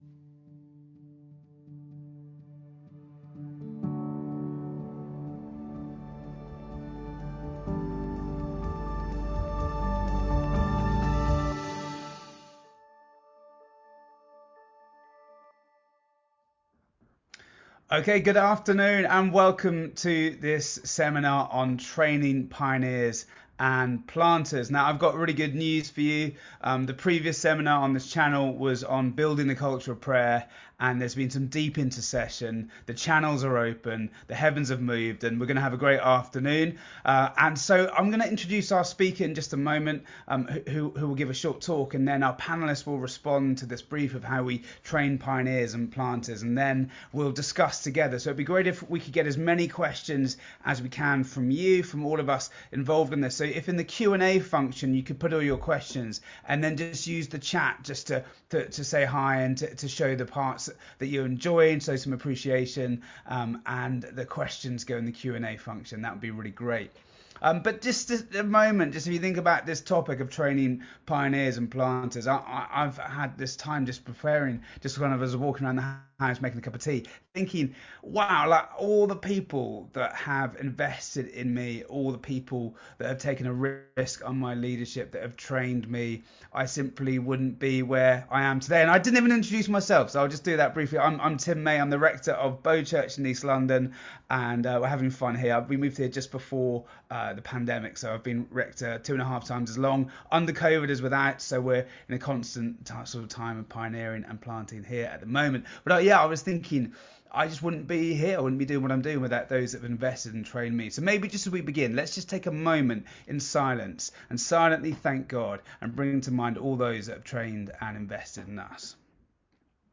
Multiply 2021 Seminars: Training pioneers and planters - CCX